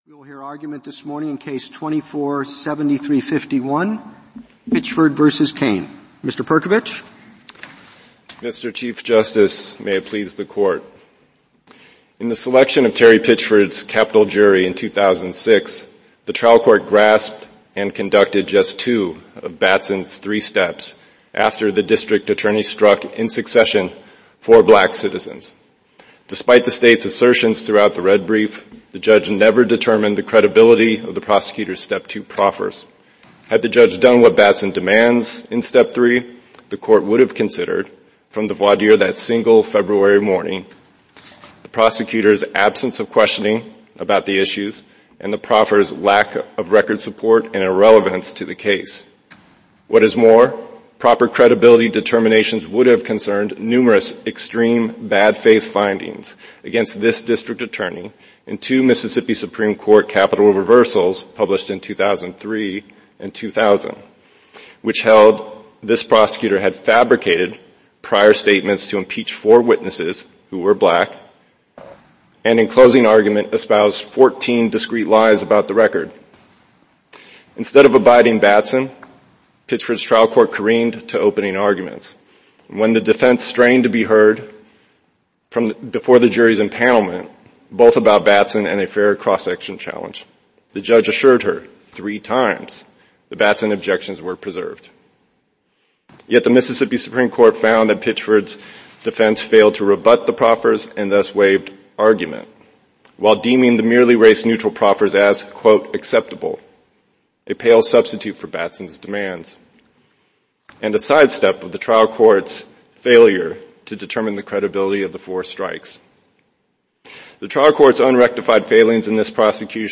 U.S. Supreme Court Oral Arguments